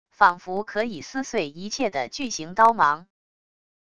仿佛可以撕碎一切的巨型刀芒wav音频